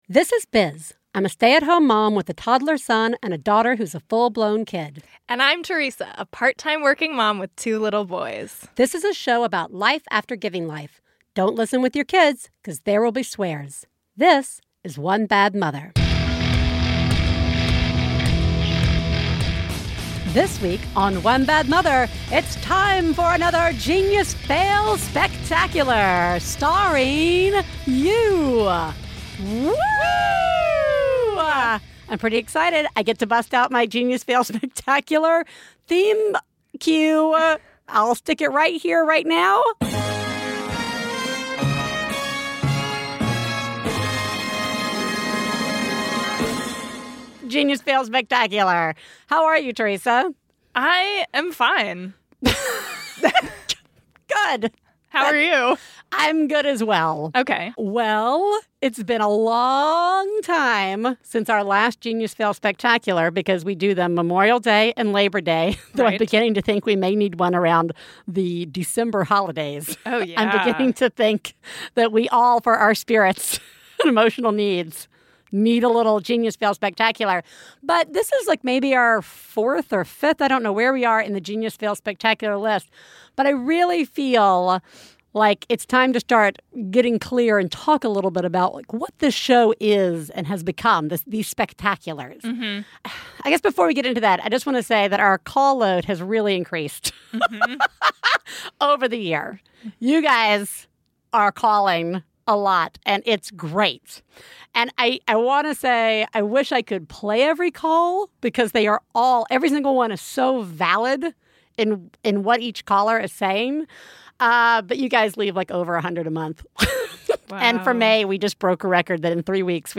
Comedy
Time for another episode of One Bad Mother devoted entirely to your most epic listener calls.